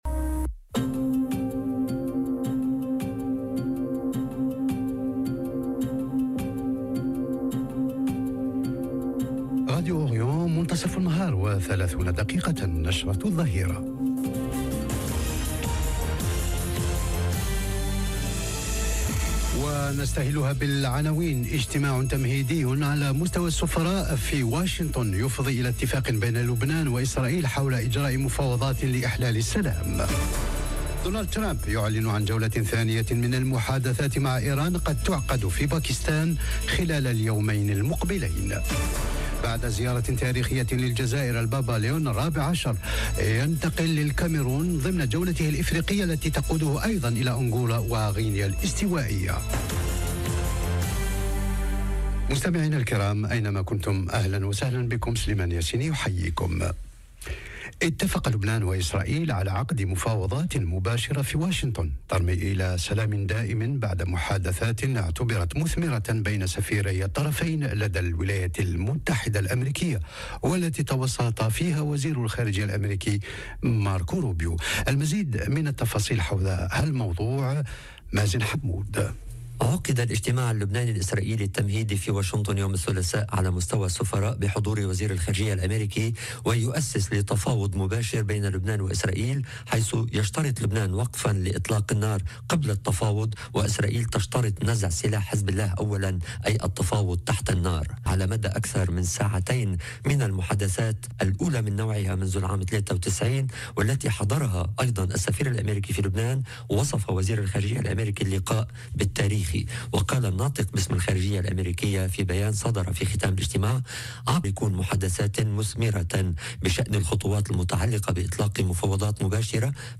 نشرة الظهيرة..